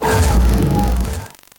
Fichier:Cri 0623 NB.ogg